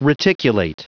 Prononciation du mot reticulate en anglais (fichier audio)
Prononciation du mot : reticulate